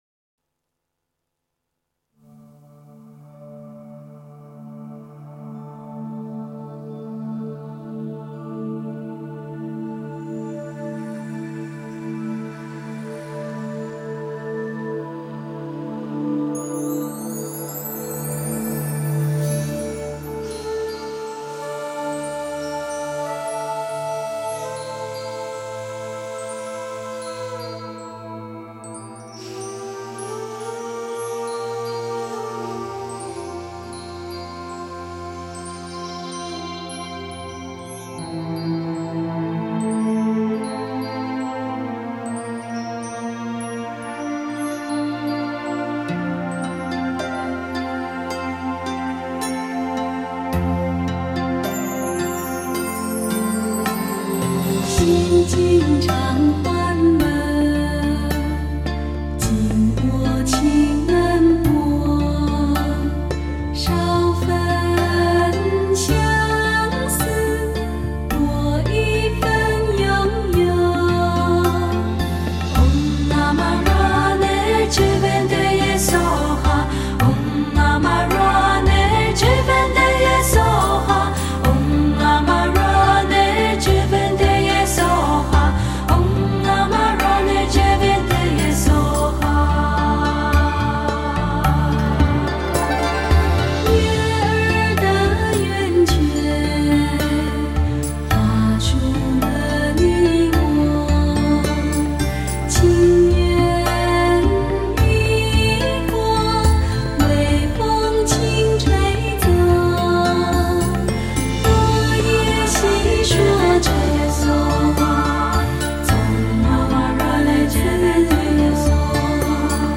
佛音
佛教音乐